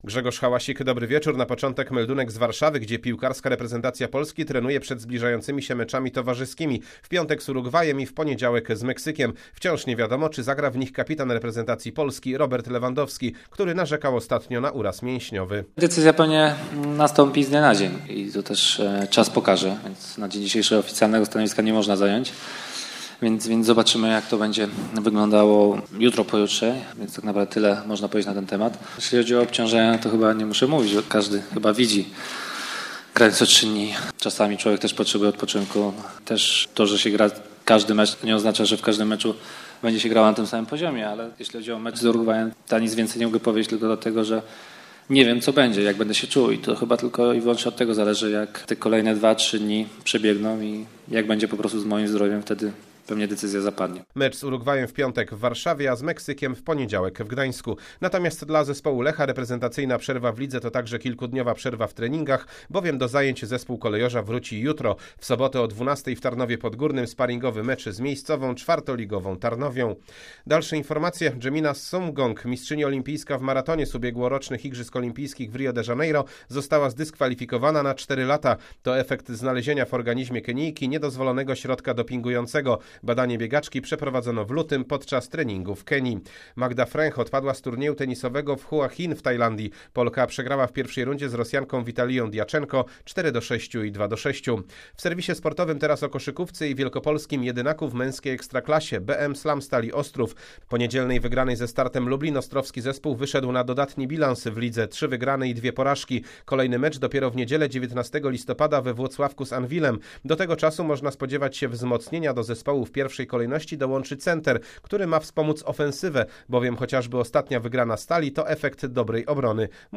07.11 serwis sportowy godz. 19:05